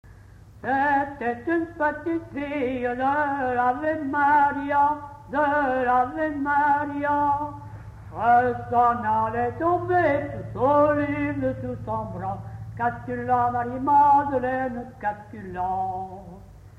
danse : ronde : grand'danse
Pièce musicale inédite